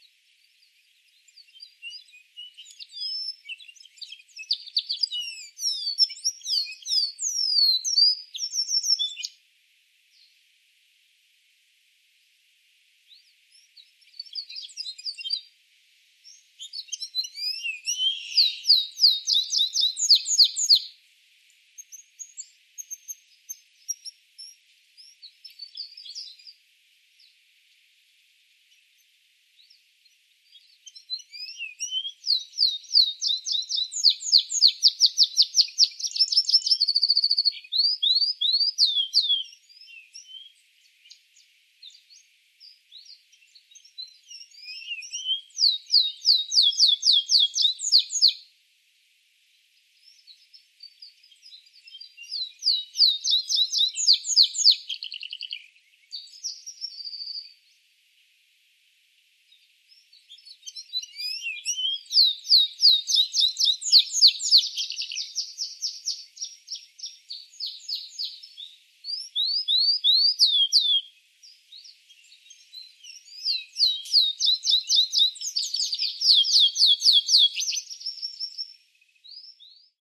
На этой странице вы можете слушать и скачивать звуки канарейки – удивительно мелодичные и чистые трели, которые украсят ваш день.
Звук пения канарейки и канарского вьюрка